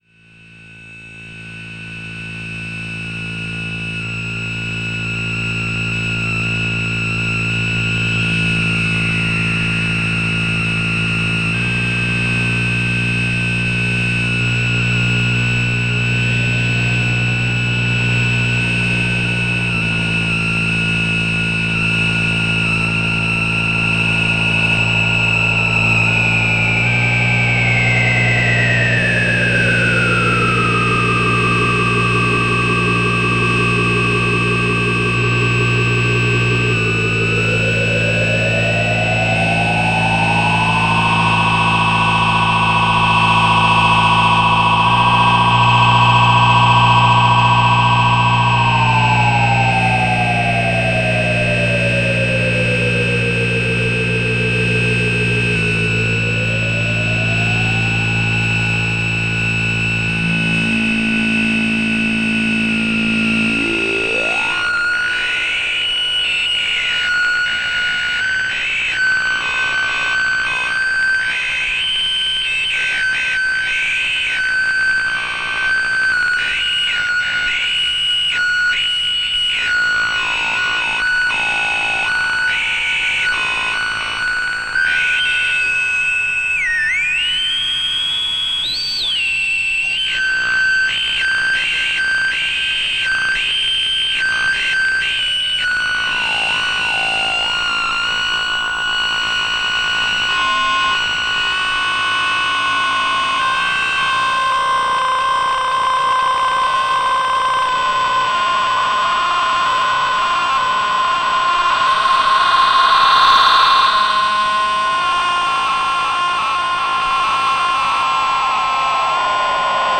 synthesizer
such noise is clearly audible in the recordings.
• Genre: Death Industrial / Power Electronics